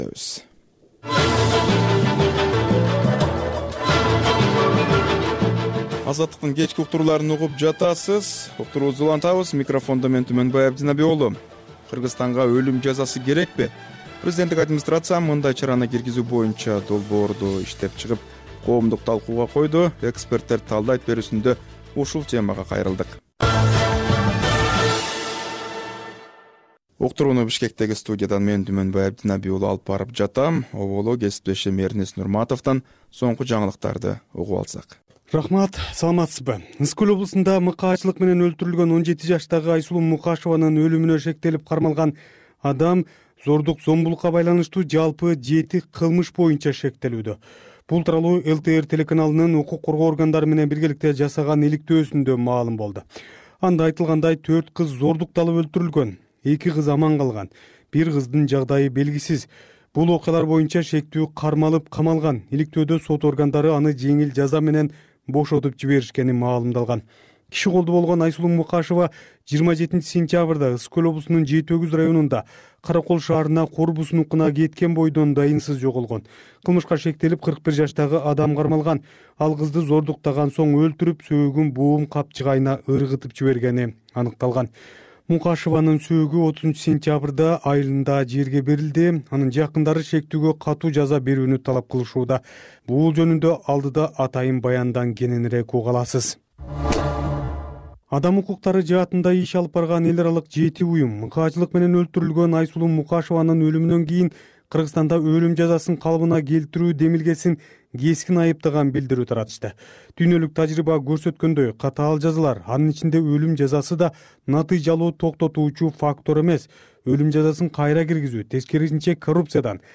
Бул үналгы берүү ар күнү Бишкек убакыты боюнча саат 18:30ден 19:00га чейин обого түз чыгат.